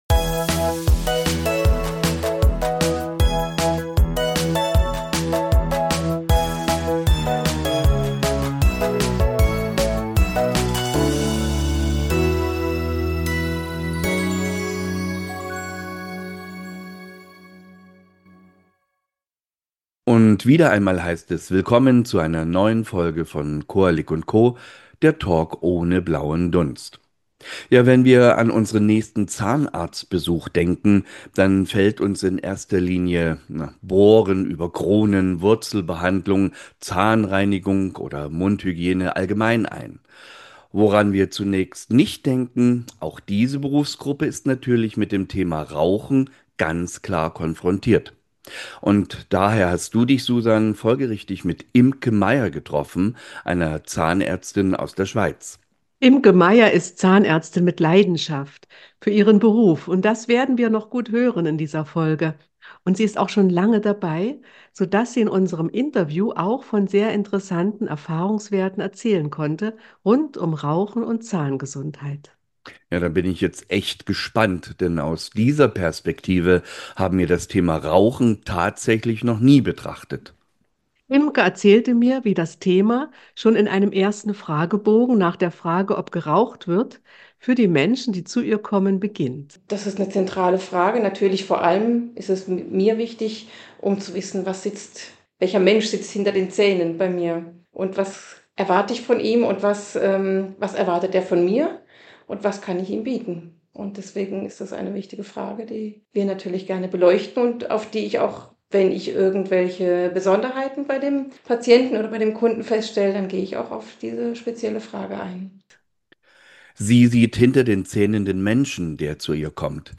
Das Gespräch beleuchtet auch orale Nikotinprodukte wie Snus, E-Zigaretten und Nikotinbeutel